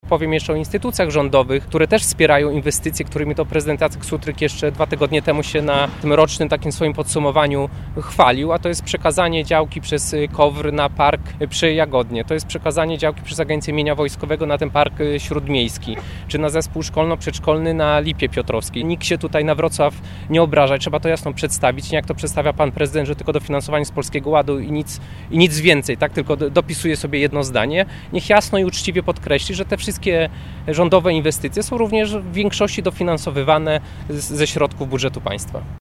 Podczas konferencji skierowano apel do Prezydenta Wrocławia.